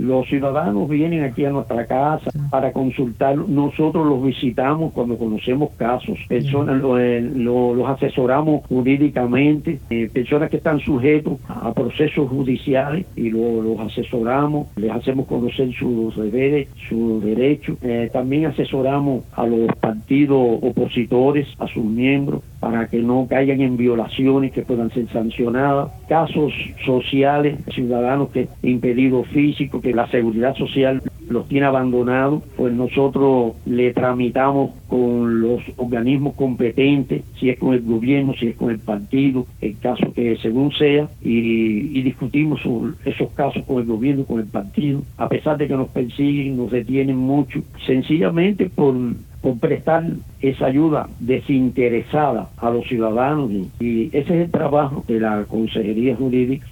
"Los ciudadanos vienen aquí a nuestra casa para consultarnos. Nosotros los visitamos cuando conocemos casos, los asesoramos jurídicamente. Personas que están sujetas a procesos judiciales (...) les hacemos concoer sus derechos", explicó el jurista a Radio Martí.